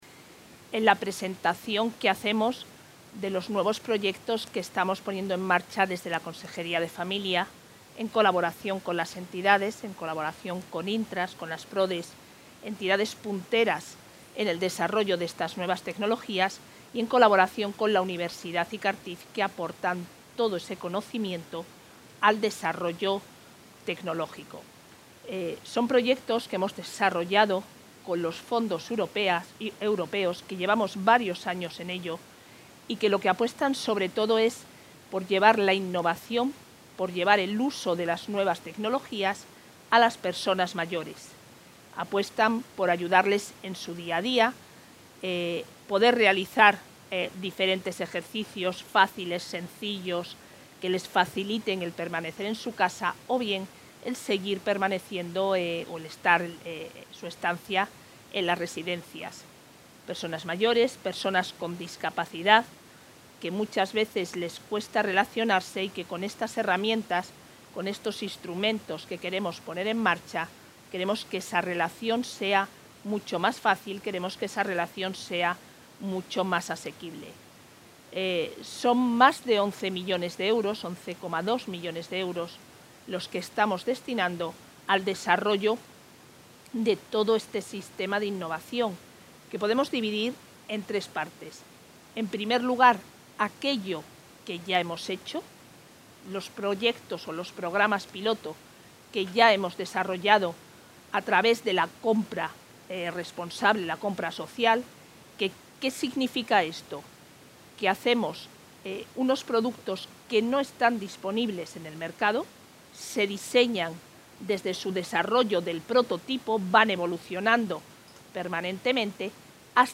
La consejera de Familia e Igualdad de Oportunidades, Isabel Blanco, ha presentado hoy en la sede de la Fundación Intras, en Valladolid, un...
Intervención de la consejera.